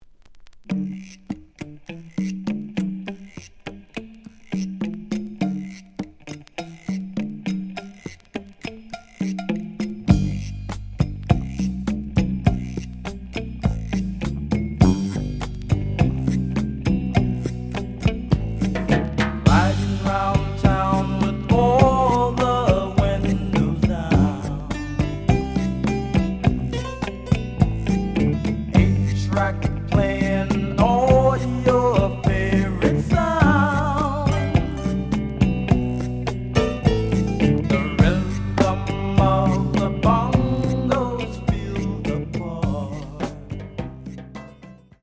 試聴は実際のレコードから録音しています。
The audio sample is recorded from the actual item.
●Genre: Funk, 70's Funk